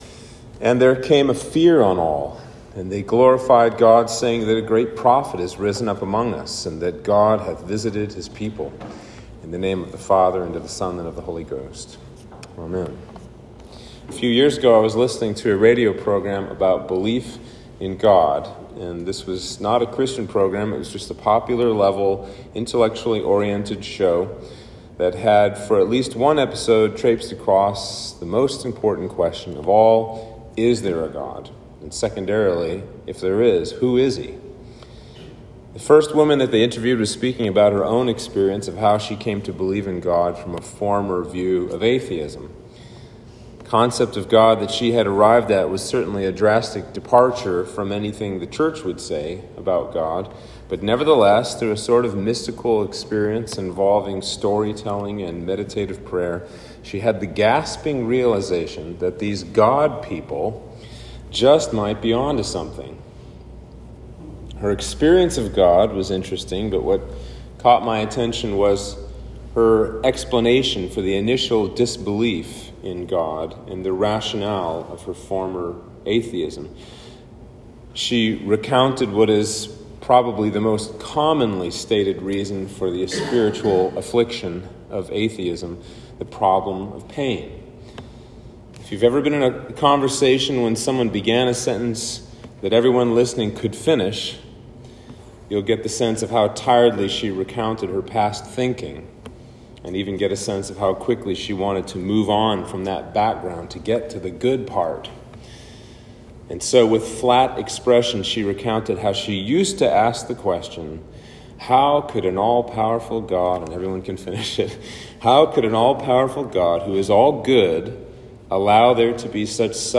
Sermon for Trinity 16